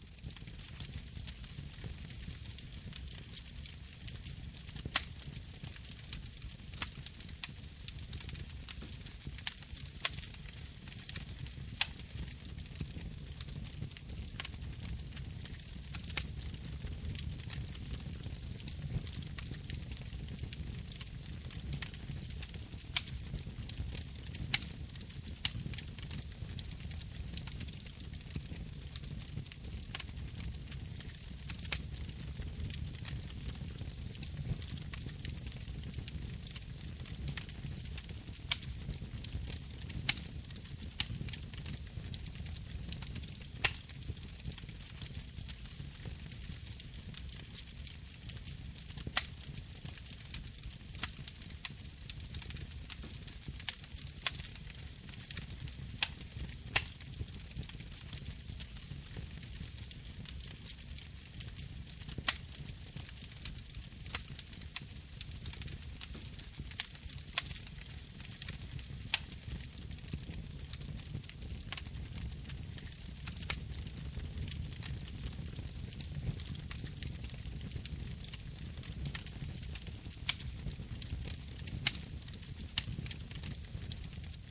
panorama-left-kamin.ogg